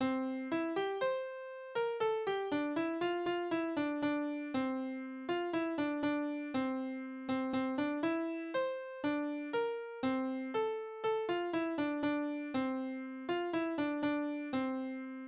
Spottverse auf Namen: Trine
Tonart: F-Dur
Taktart: 4/4
Kommentar Einsender*in: Früher überall gespielter Marsch oder Tanz.